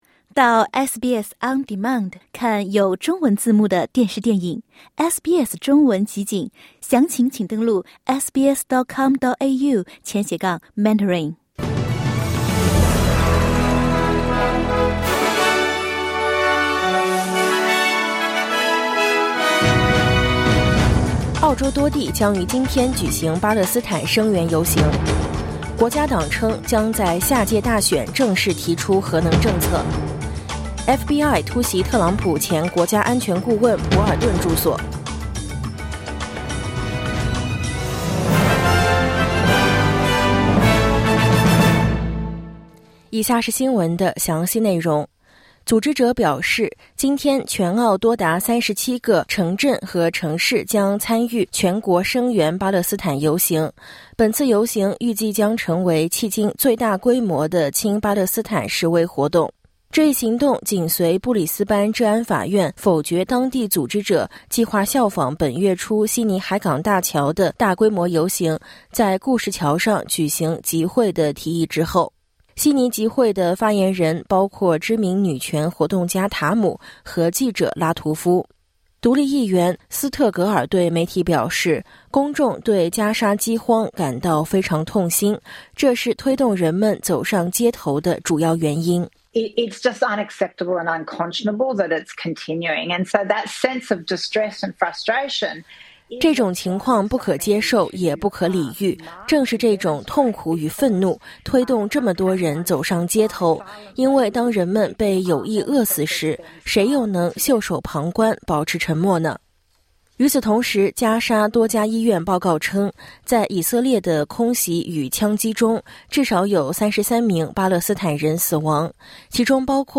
SBS早新闻（2025年8月24日）